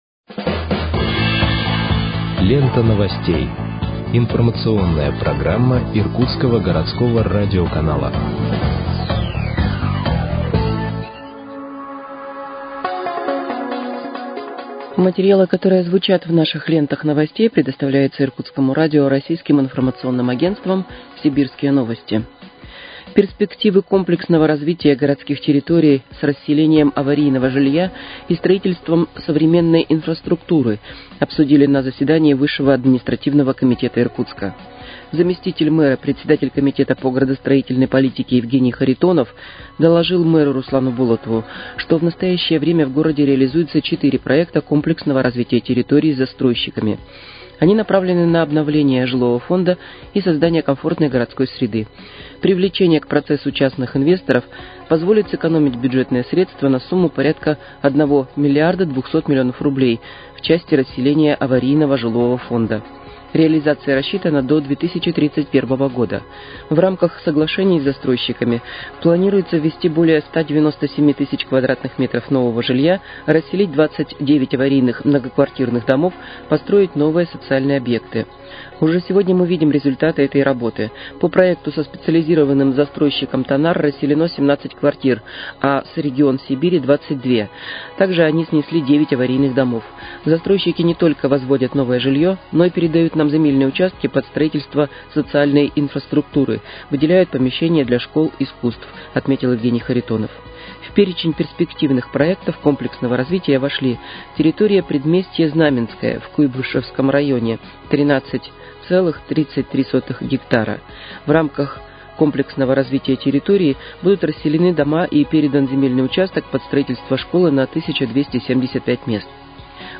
Выпуск новостей в подкастах газеты «Иркутск» от 14.07.2025 № 2